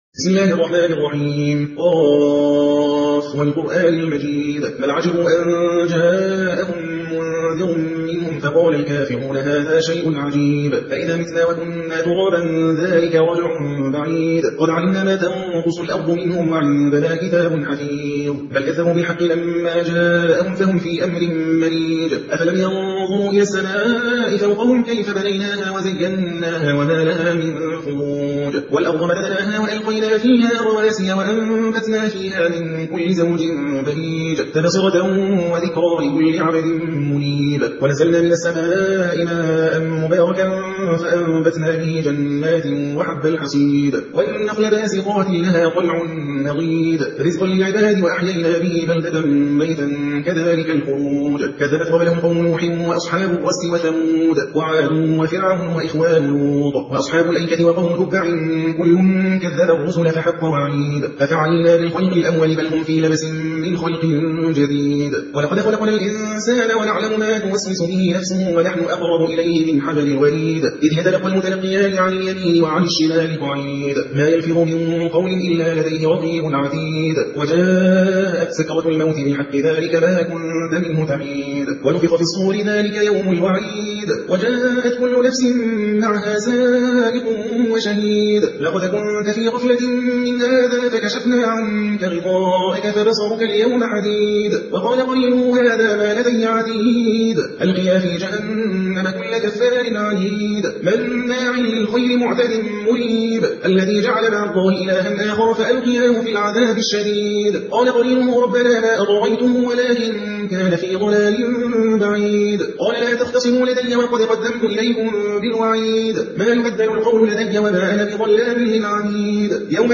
مصحف قراءة الحدر